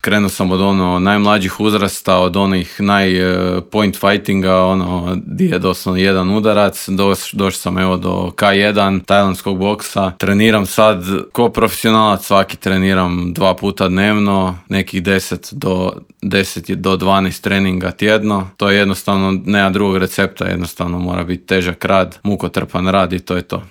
Intervjui Media servisa u tjednu na izmaku: Od vršnjačkog nasilja do sportskih uspjeha